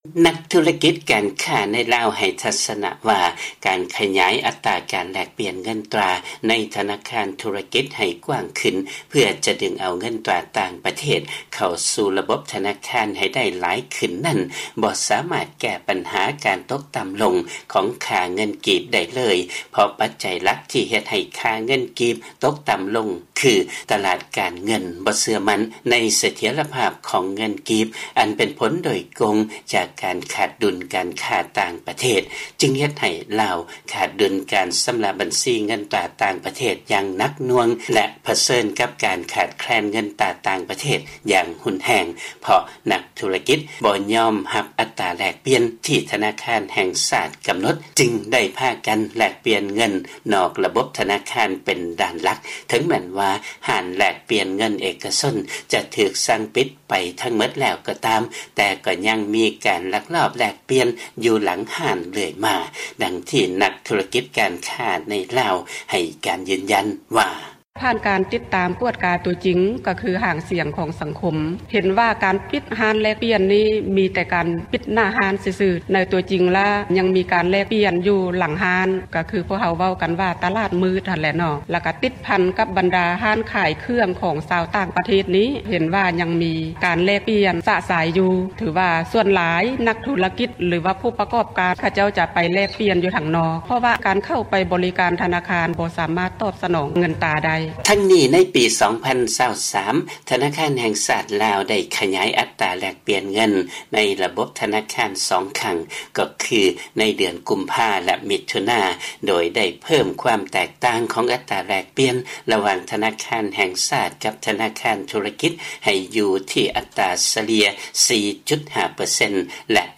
ຟັງລາຍງານ ການແລກປ່ຽນເງິນຕາ ນອກລະບົບທະນາຄານໃນລາວ ຍັງຄົງເກີດຂຶ້ນຢ່າງກວ້າງຂວາງ ແລະທາງການ ບໍ່ສາມາດຄວບຄຸມໄດ້ເລີຍ